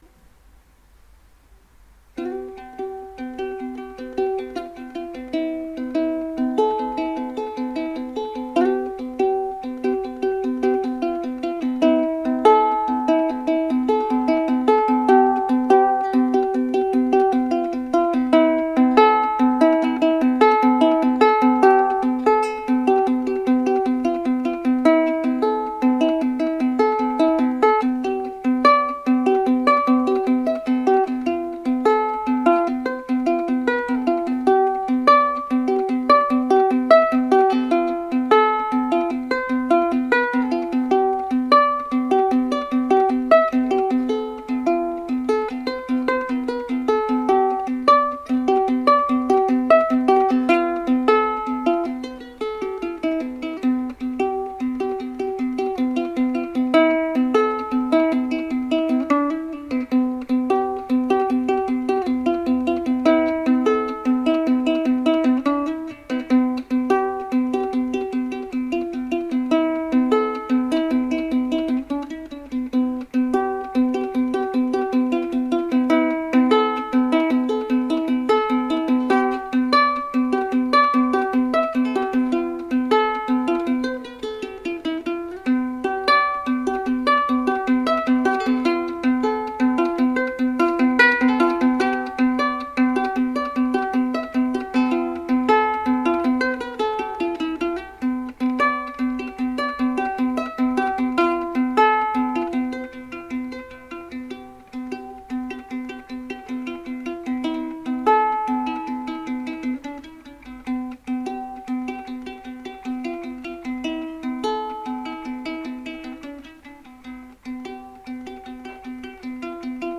其他音乐
该BGM音质清晰、流畅，源文件无声音水印干扰